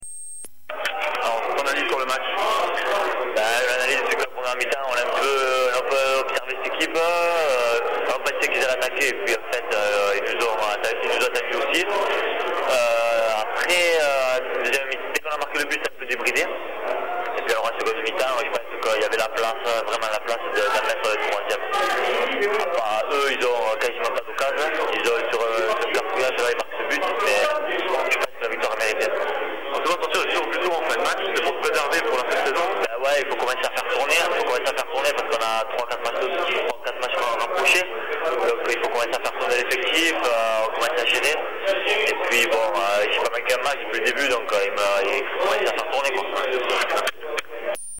Voici les réactions recueillies après la rencontre FC Sète - AS Cannes.